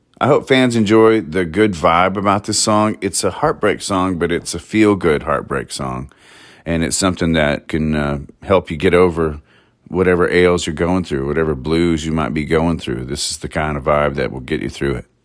Audio / Phillip Sweet of Little Big Town, who co-wrote the group’s latest single “Hell Yeah,” says the tune is a “feel-good heartbreak song.”